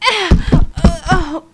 death3.wav